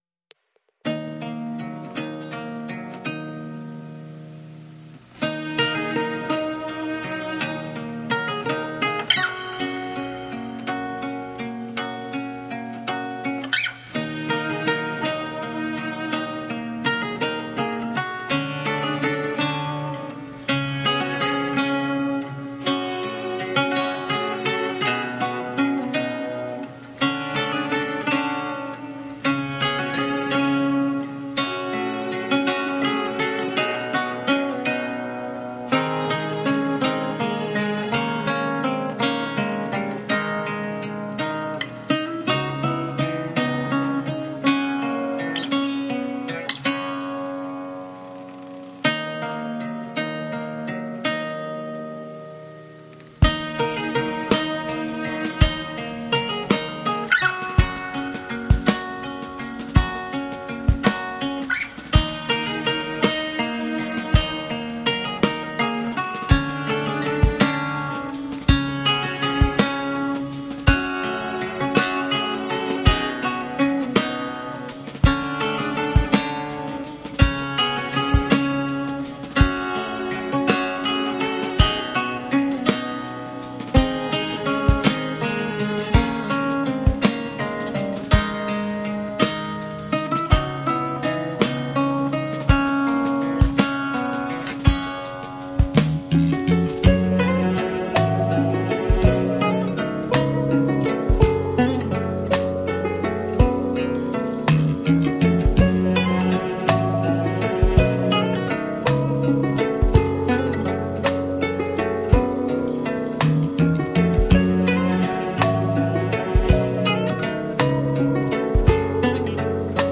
Soul soothing acoustic guitar songs.